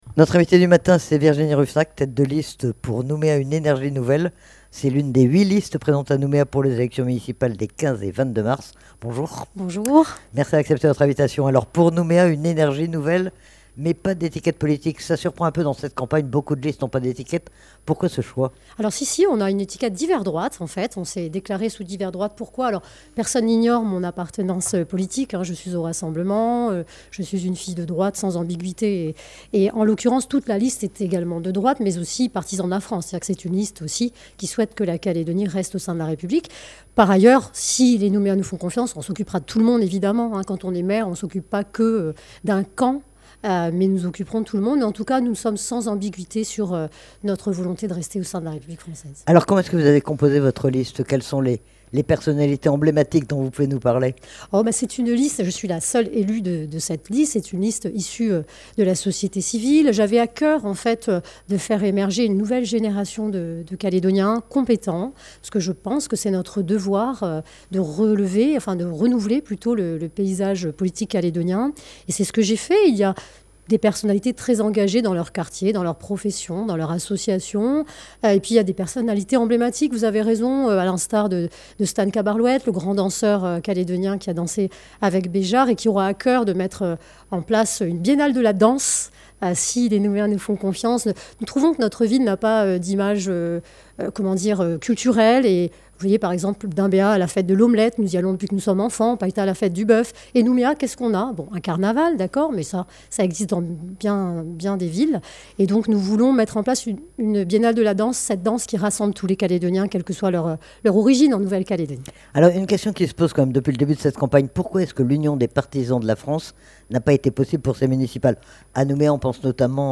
Elle est interrogée sur son projet et sur la composition de sa liste.